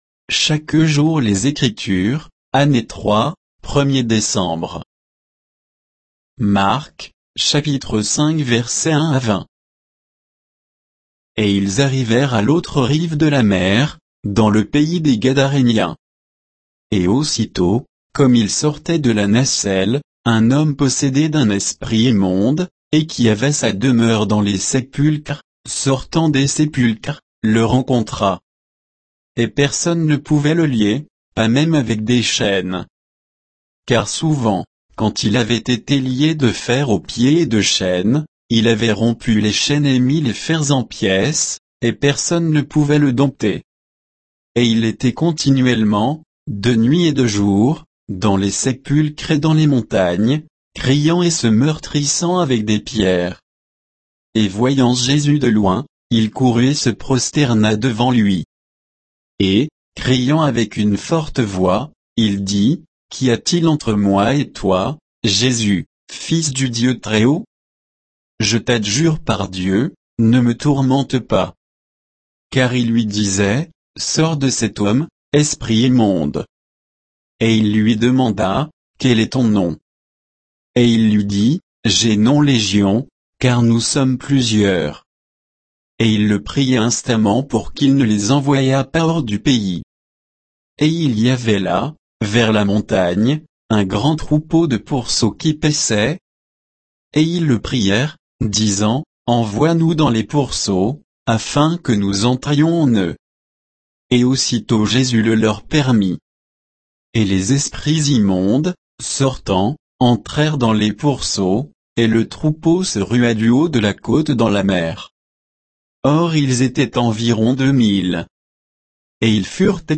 Méditation quoditienne de Chaque jour les Écritures sur Marc 5, 1 à 20